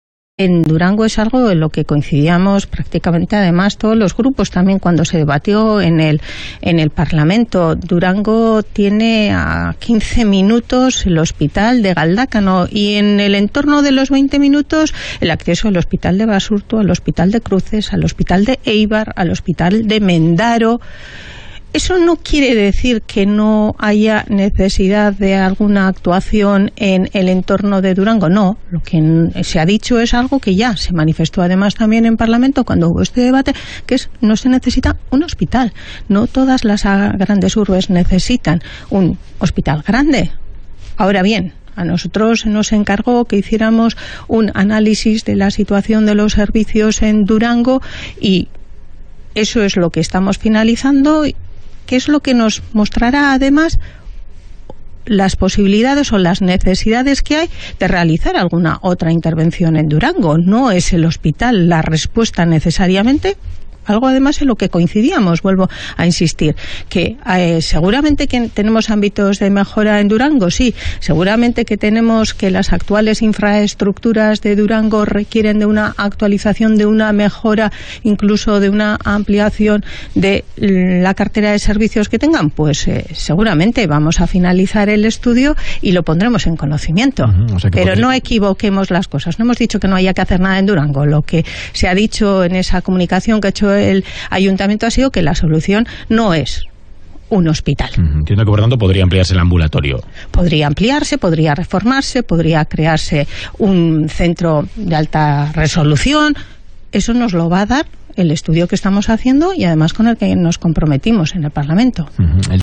Declaraciones de la Consejera de Sanidad en Radio Euskadi 09/02/2023